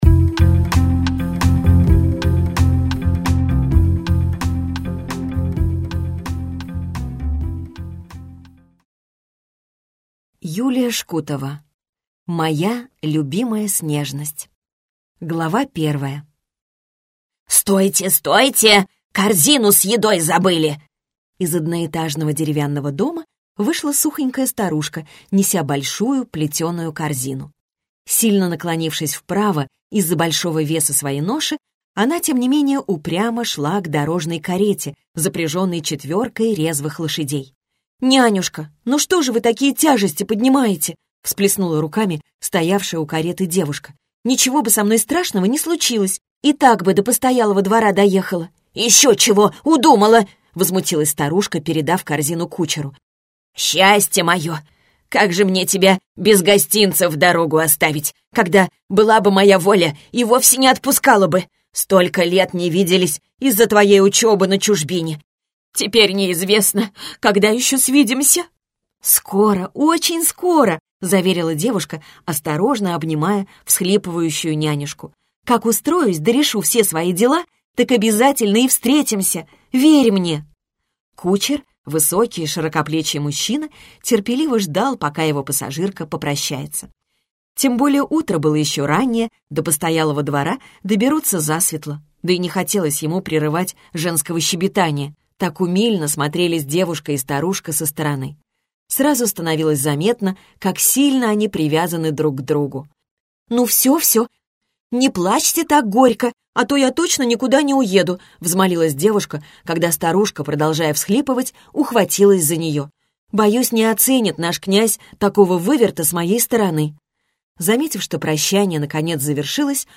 Прослушать фрагмент аудиокниги Моя любимая (с)нежность Юлия Шкутова Произведений: 5 Скачать бесплатно книгу Скачать в MP3 Вы скачиваете фрагмент книги, предоставленный издательством